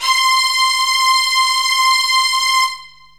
STRINGS 0006.wav